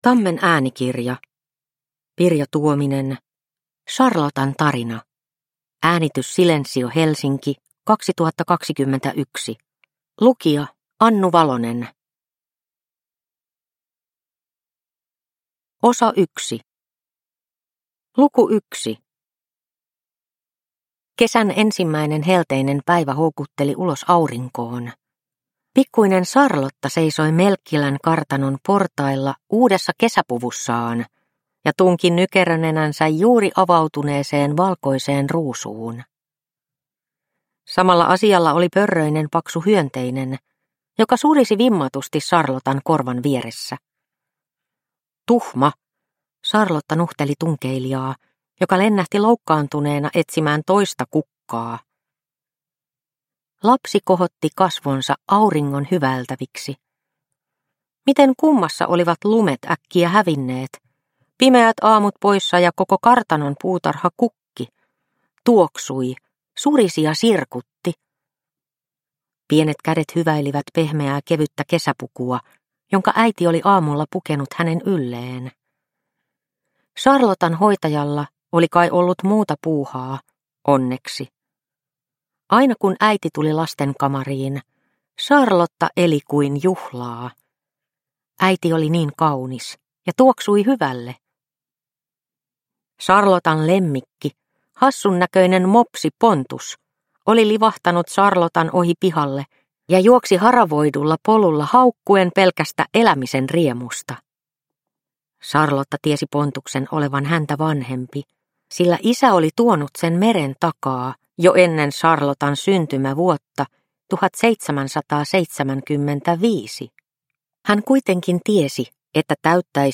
Charlotan tarina – Ljudbok – Laddas ner